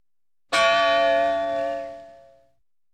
Single Bell Sound Effect Free Download
Single Bell